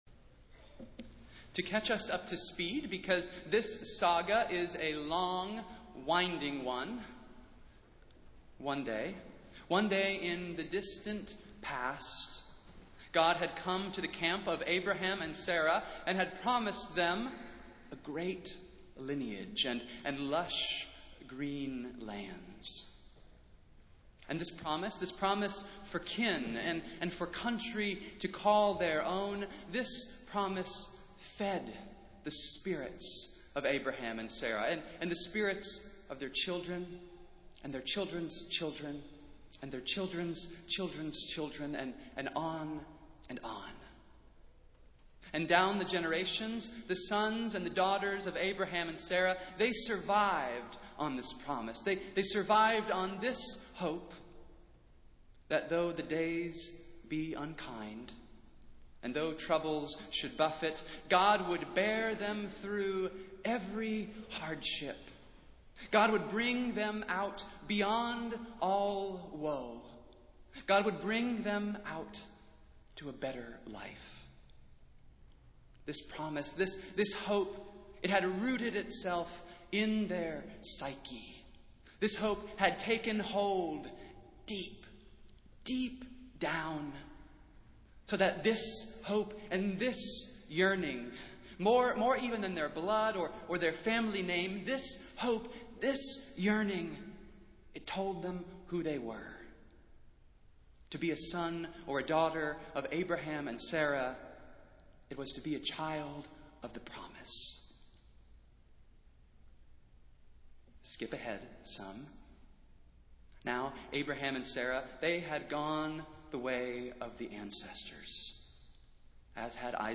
Festival Worship - Nineteenth Sunday after Pentecost